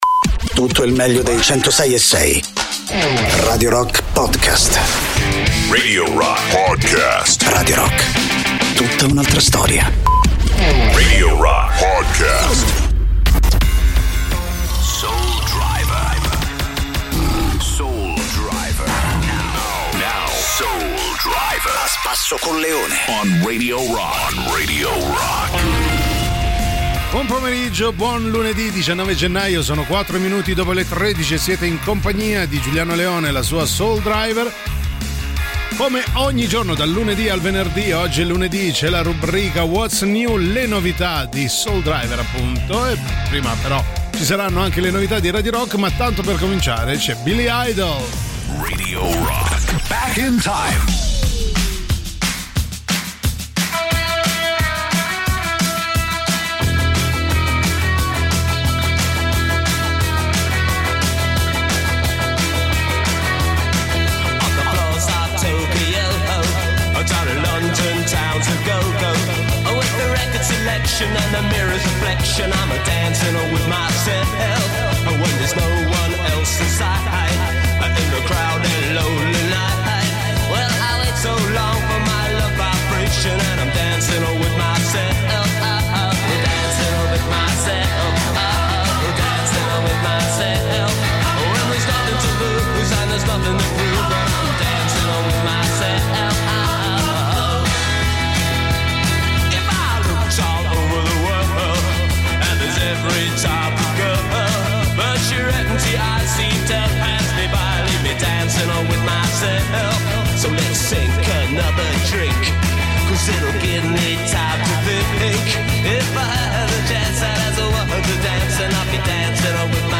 in diretta dal lunedì al venerdì, dalle 13 alle 15, con “Soul Driver” sui 106.6 di Radio Rock.